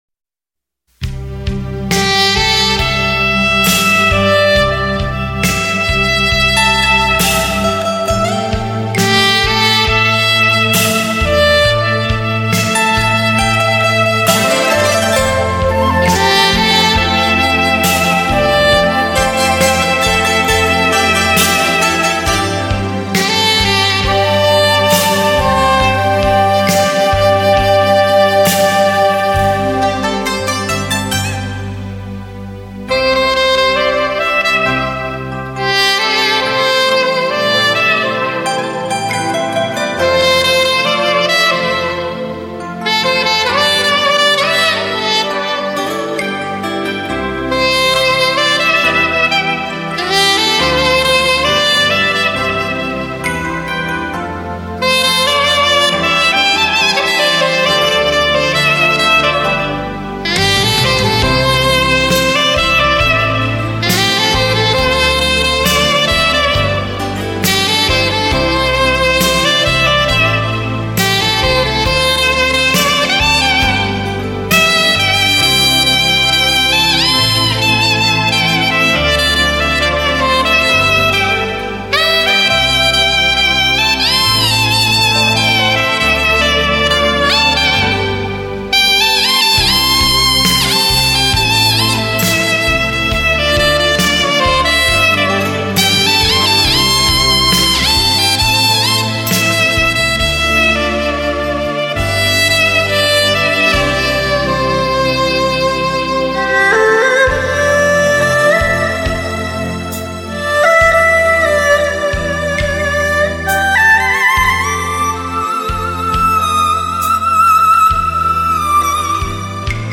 专辑格式：DTS-CD-5.1声道
跨世紀之約，邀你進入薩克斯風的音樂季，金曲典藏，延伸聆聽，全新感動。